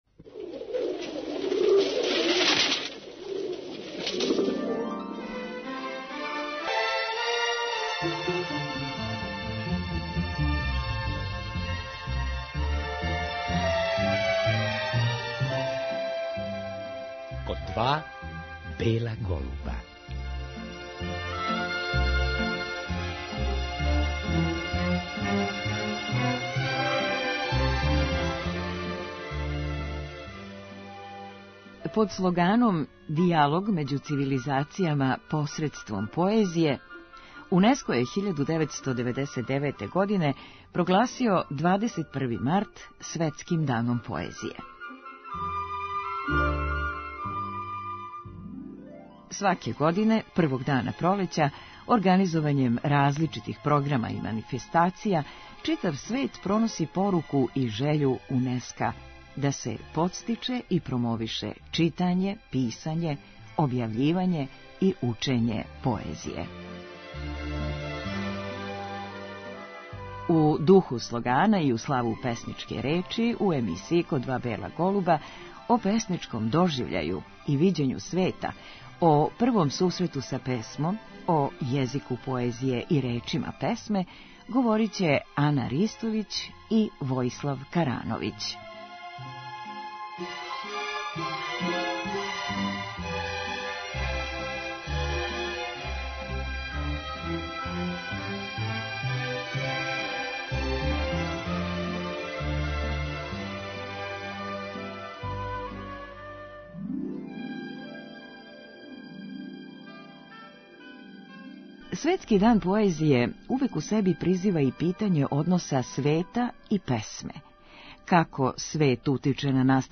Поводом Светског дана поезије, о песништву говоре и стихове читају савремени српски песници